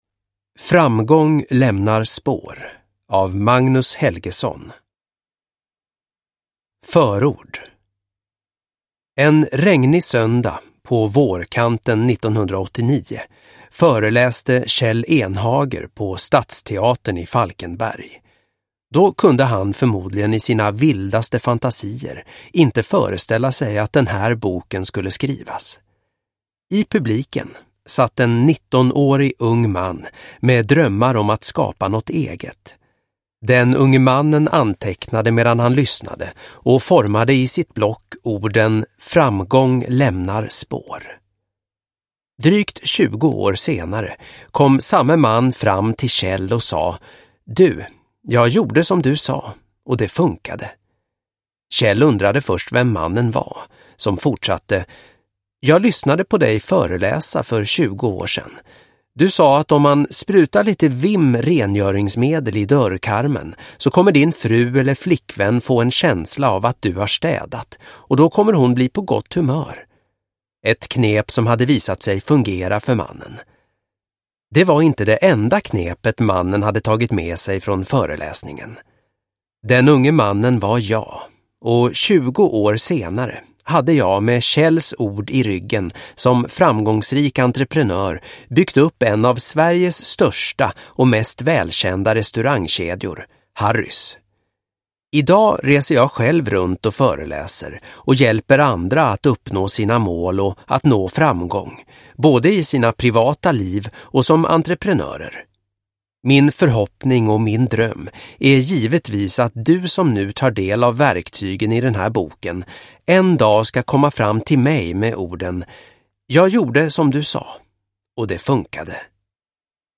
Framgång lämnar spår – Ljudbok – Laddas ner